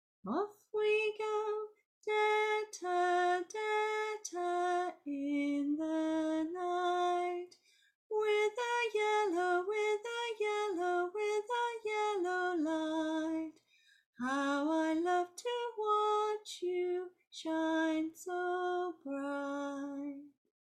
Here’s the song with the English words:
It’s in quadruple simple metre, so four ta beats in a bar.